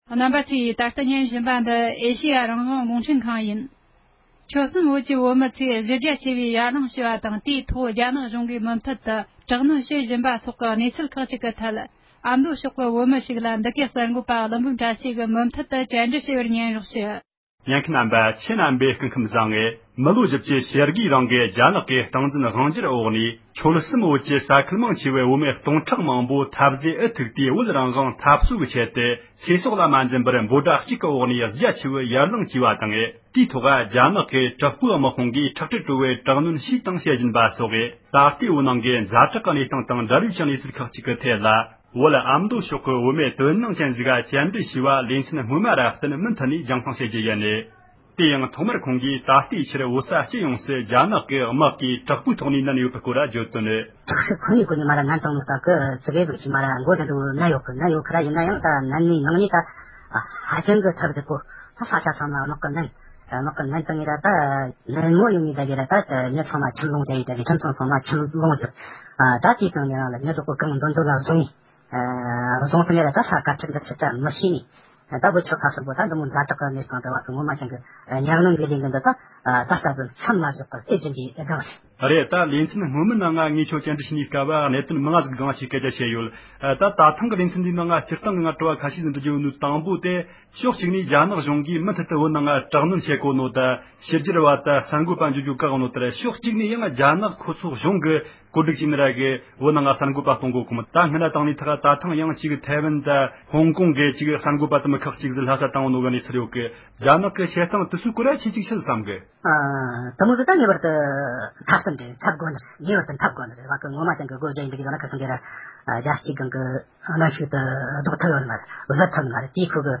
དོ་སྣང་ཅན་ཞིག་ལ་བཀའ་དྲི་ཞུས་པ་ཞིག་གསན་རོགས་གནང༌།།